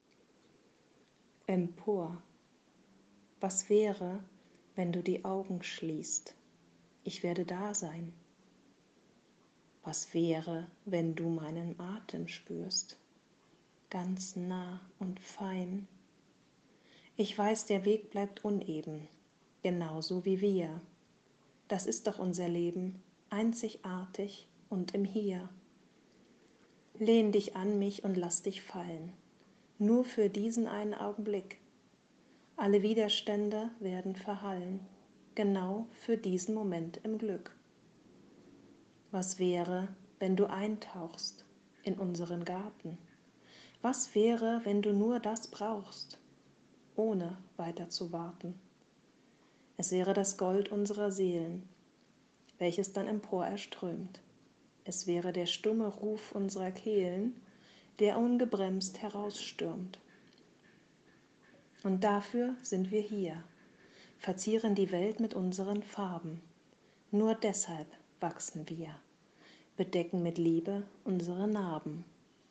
Ich lese auch vor
Meine Gedichte zum Hören